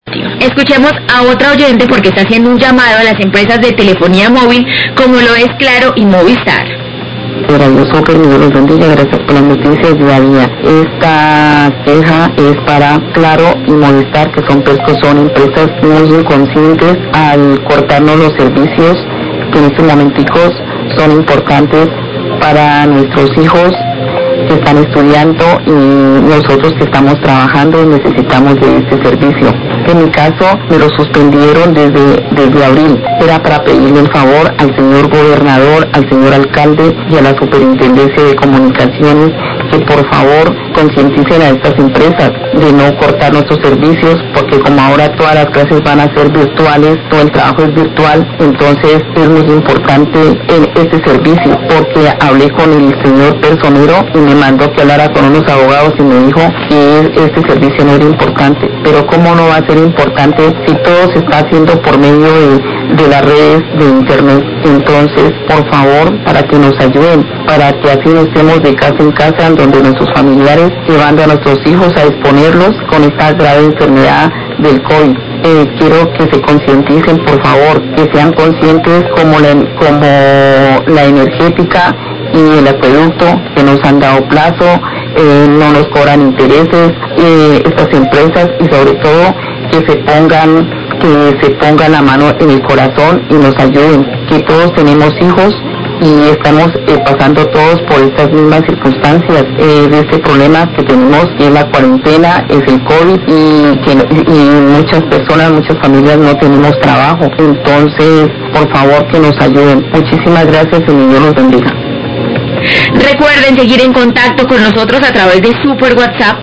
Radio
Oyente se queja de las empresas de telefonía Claro y Movistar, ya que cortan el servicio y ahora que seguirán las clases y el trabajo virtual por el coronavirus, que sean conscientes como lo hizo la Compañía Energética y el Acueducto y Alcantarillado que dieron plazos de pago y sin intereses.